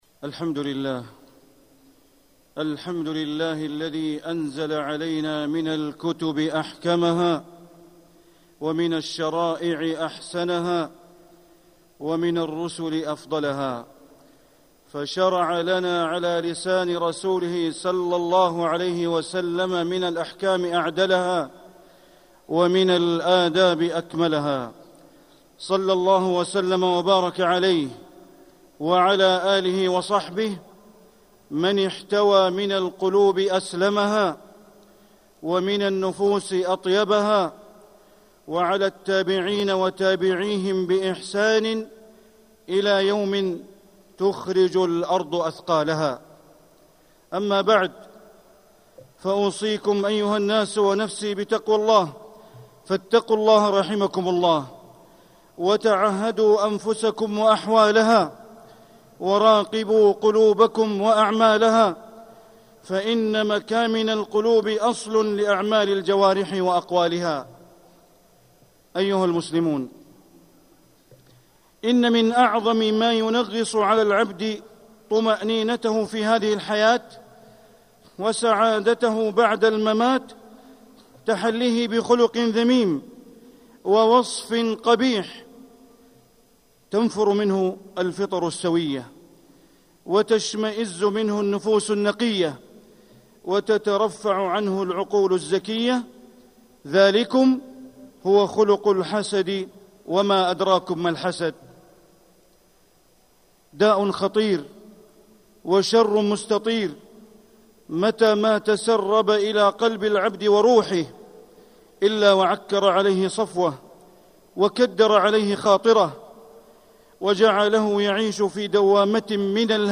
مكة: ذم الحسد - بندر بن عبد العزيز بليلة (صوت - جودة عالية. التصنيف: خطب الجمعة